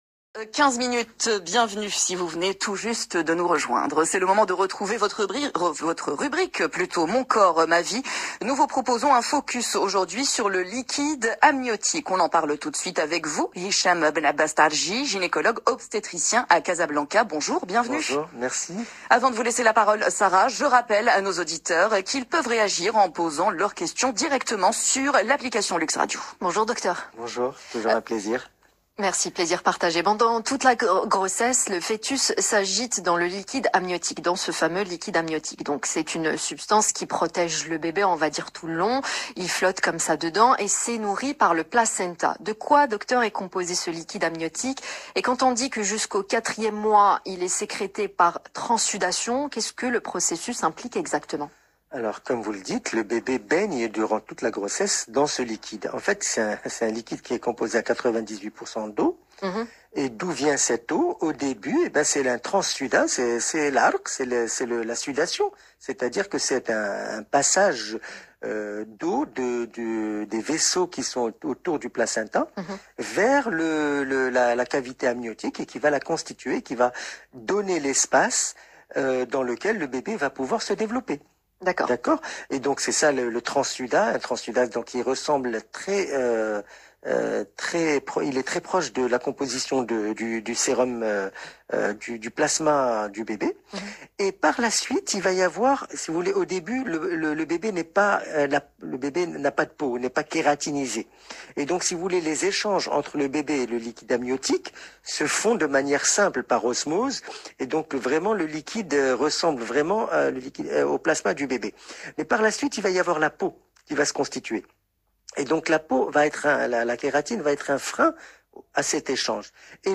Plus d’explications dans cette interview de l’Heure Essentielle de LUXE RADIO du 02 nov 2021